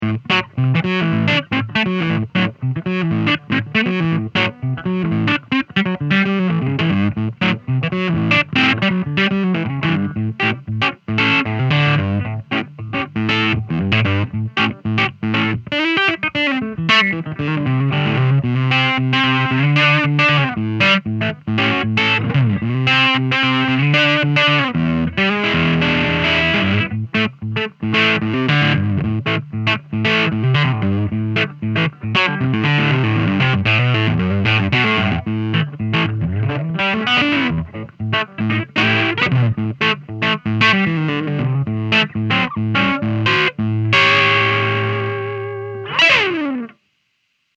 Funky overdrive